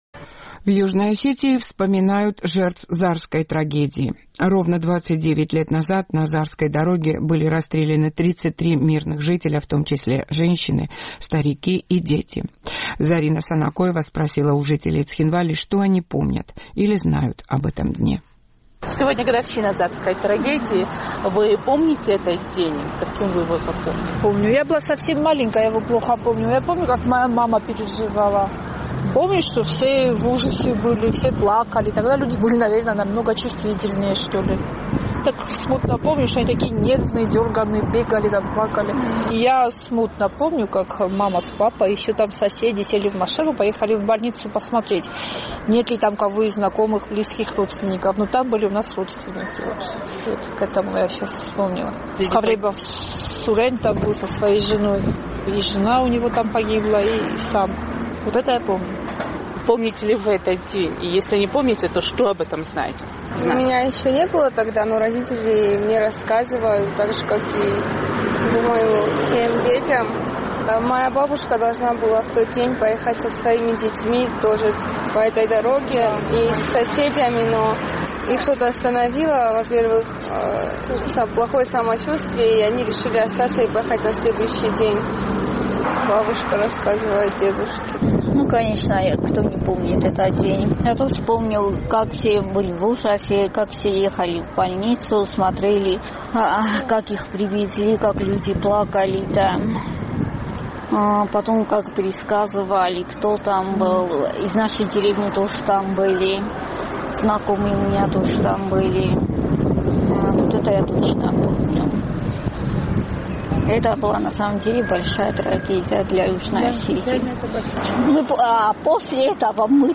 В Южной Осетии вспоминают жертв Зарской трагедии. 29 лет назад, 20 мая 1992 года, на Зарской дороге грузинскими вооруженными формированиями были расстреляны 33 мирных гражданина, в том числе женщины, старики и дети. «Эхо Кавказа» спросило у жителей Цхинвала, что они помнят об этом дне.